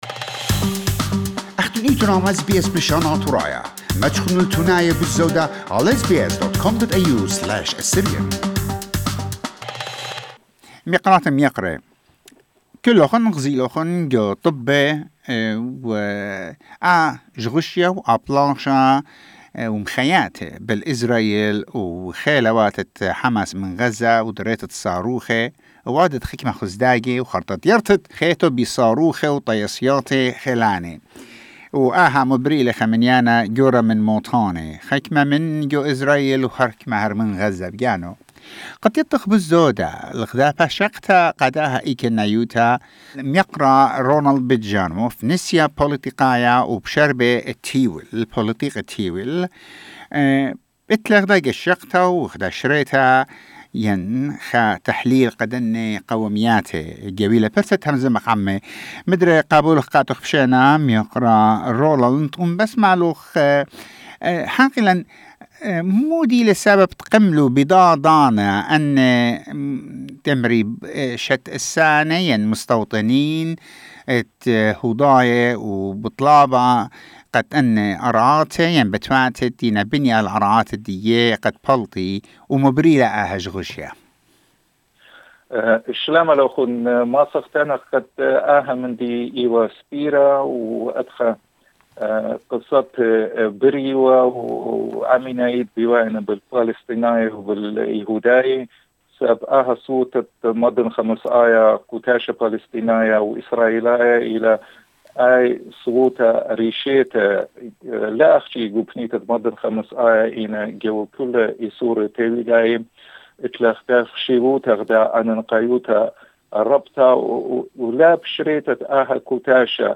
filed this report about the reason behind the latest escalations and what does the Israel want to achieve from this war.